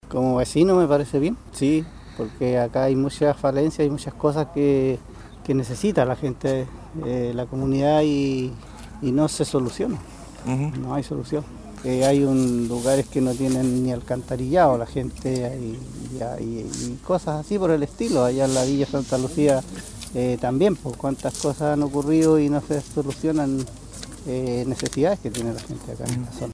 Una de las personas que durante la mañana de este jueves esperaba el paso en el puente sobre el río Blanco, vecino de Chaitén, mostró su apoyo al movimiento que llevan adelante los pobladores, señalando que hay muchas falencias en su localidad y en Villa Santa Lucía.